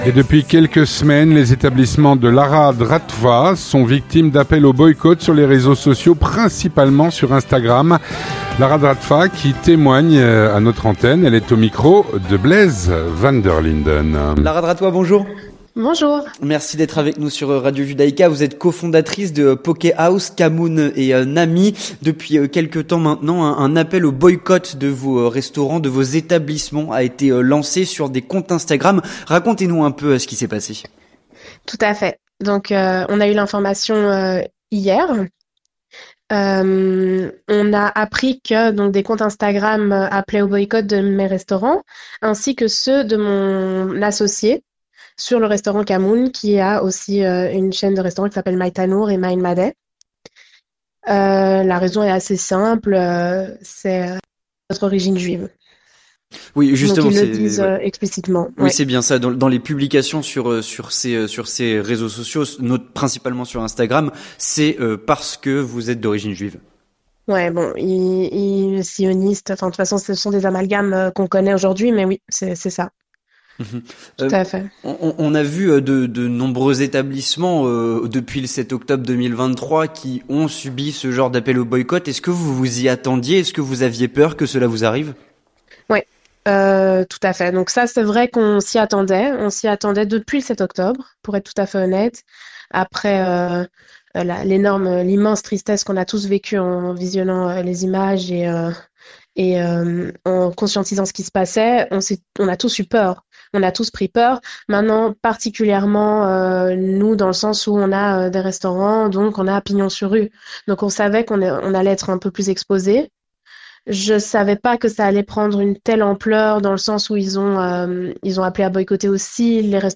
Elle témoigne à notre antenne.